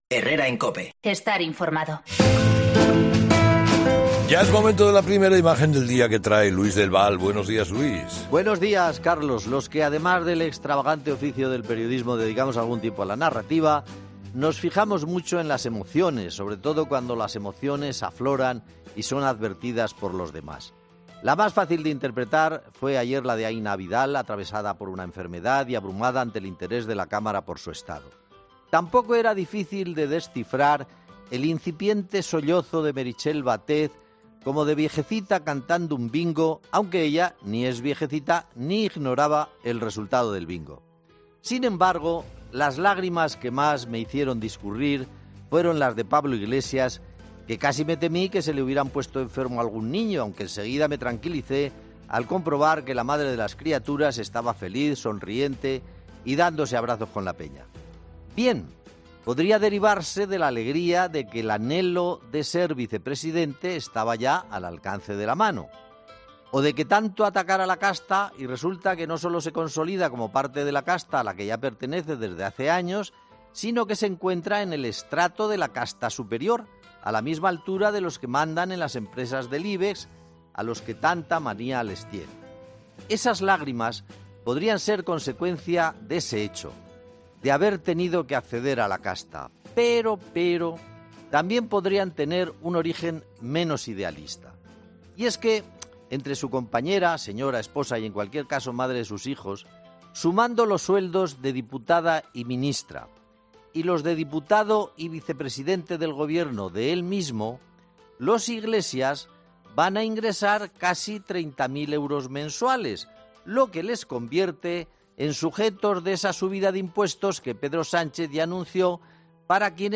El comentario de Luis el Val del miércoles 8 de enero de 2020